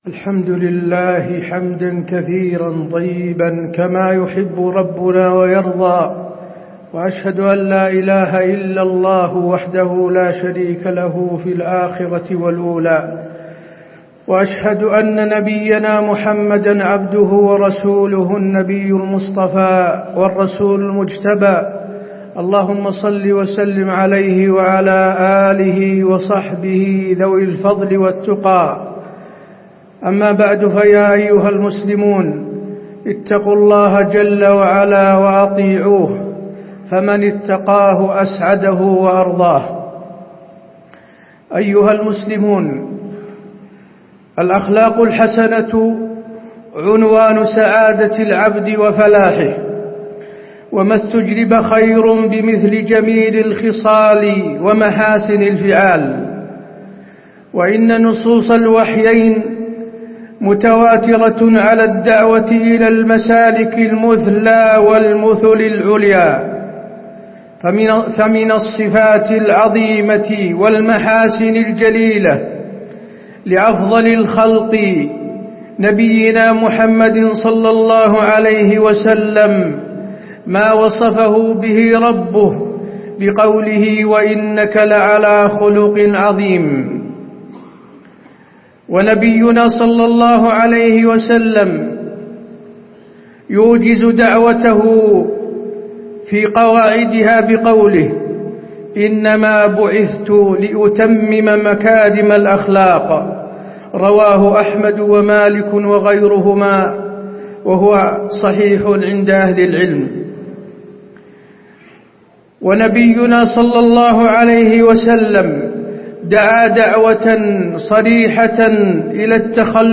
تاريخ النشر ١٣ رجب ١٤٣٩ هـ المكان: المسجد النبوي الشيخ: فضيلة الشيخ د. حسين بن عبدالعزيز آل الشيخ فضيلة الشيخ د. حسين بن عبدالعزيز آل الشيخ فضل الخلق الحسن